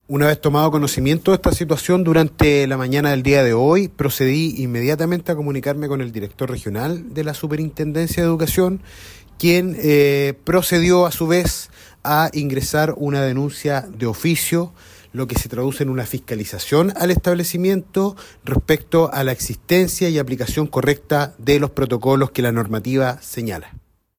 En el establecimiento declinaron referirse al tema, mientras que el seremi de Educación, Carlos Benedetti, informó que la Superintendencia de Educación inició una investigación de oficio por el caso.